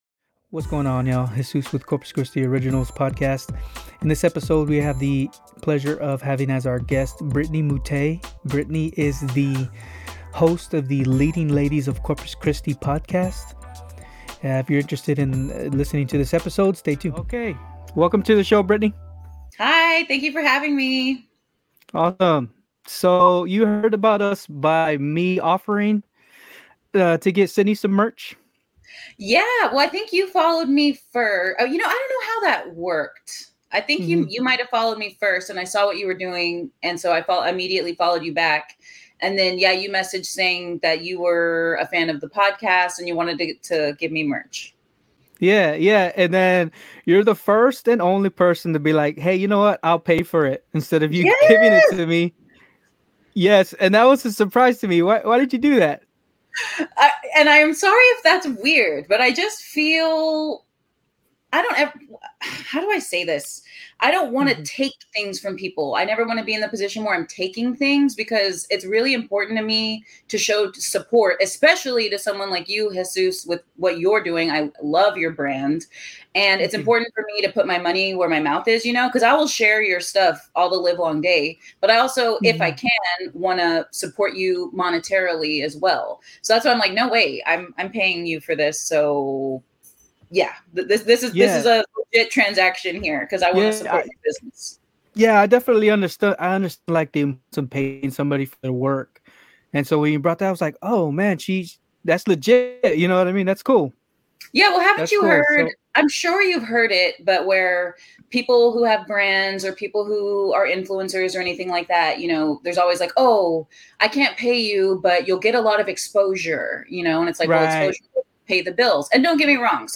However, we wanted to take the time to interview her as a leading lady of Corpus Christi on our podcast.